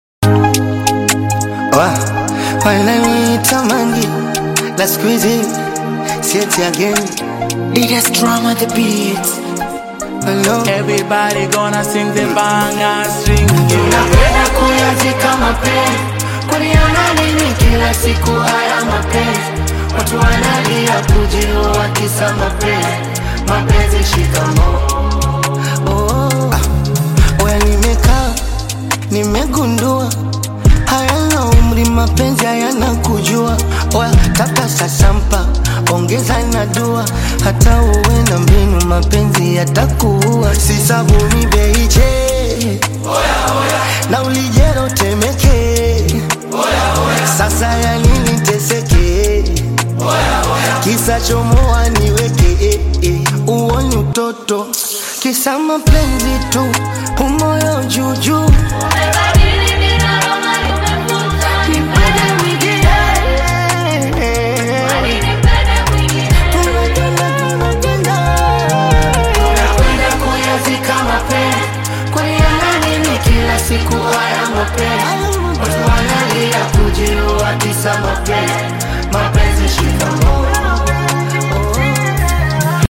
R&B style with elements of traditional African music